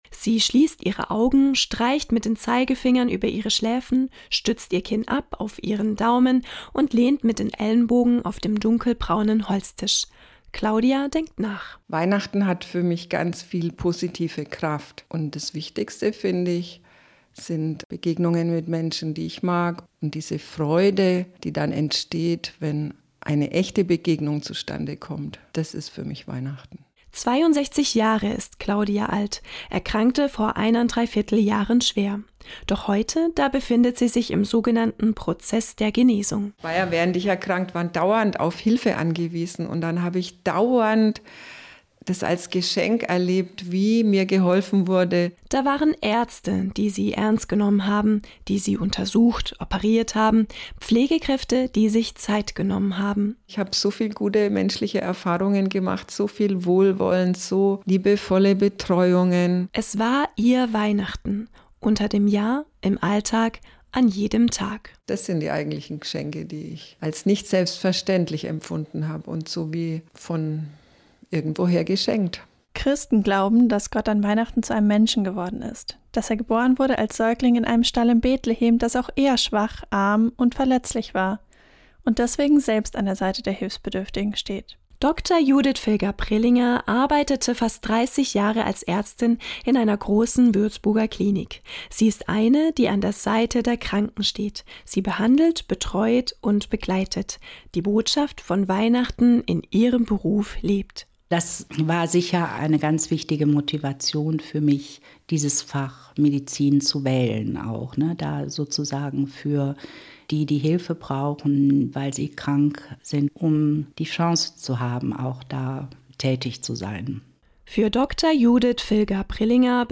Eine Ärztin und eine Erkrankte erzählen